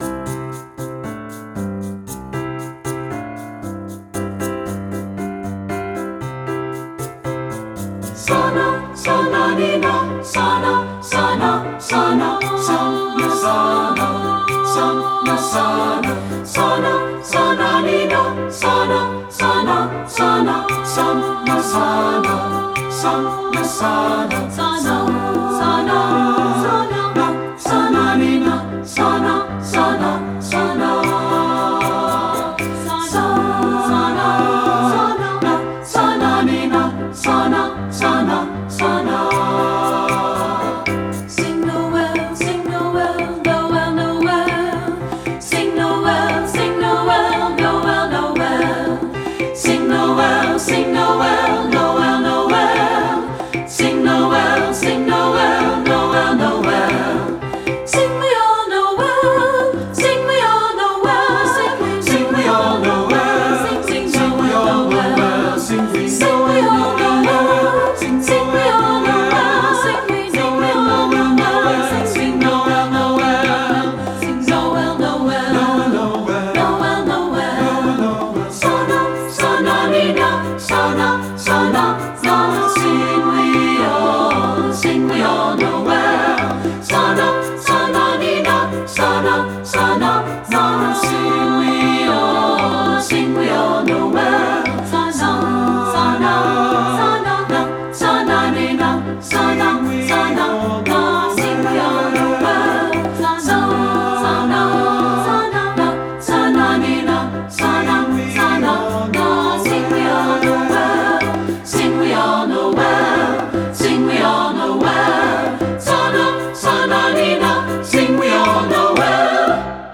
South African Folk Song
Studio Recording
Ensemble: Three-part Mixed Chorus
Key: C major
Tempo: q = 116
Accompanied: Accompanied Chorus